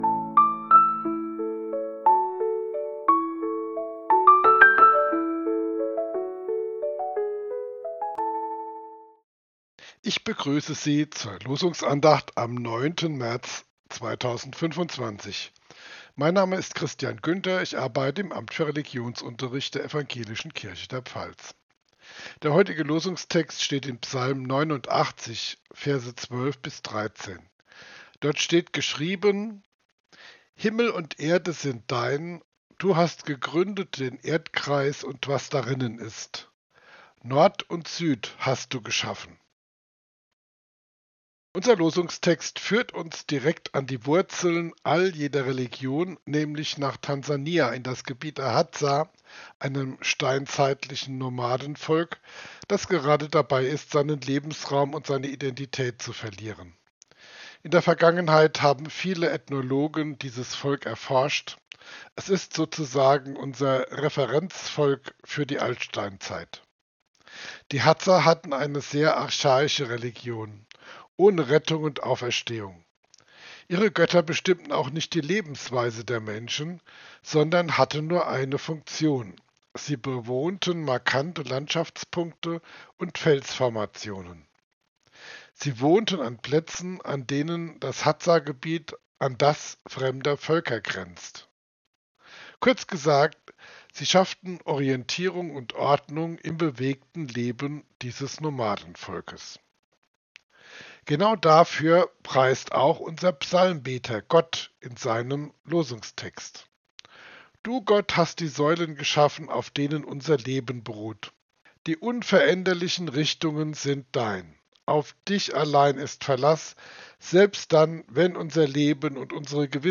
Losungsandacht für Montag, 09.03.2026 – Prot.